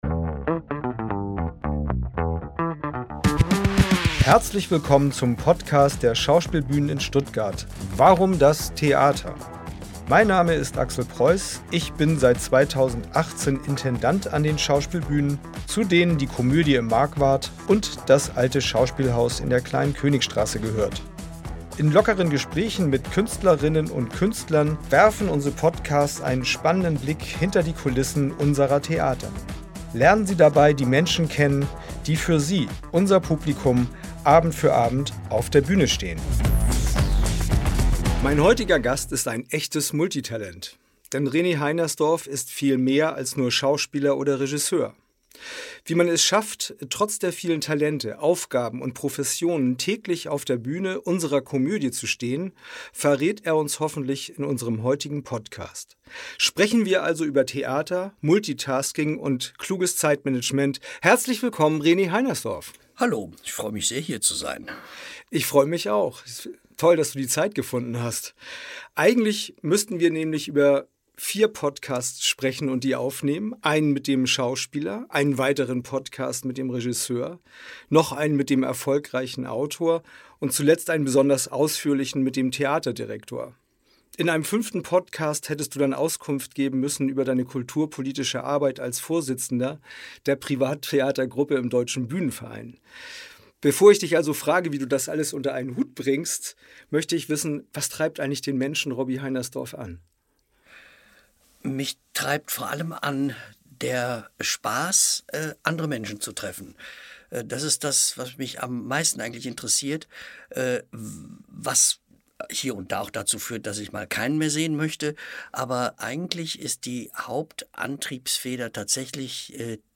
„Warum das Theater?“ Unser Schauspielbühnen-Talk präsentiert Ihnen regelmäßig Gespräche mit unseren Künstlerinnen und Künstlern. Lernen Sie die Menschen hinter den Bühnencharakteren kennen und erfahren Sie, wie Theater hinter den Kulissen funktioniert.